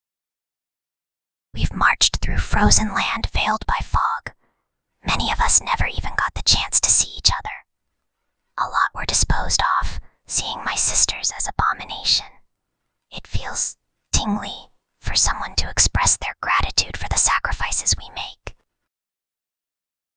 Whispering_Girl_36.mp3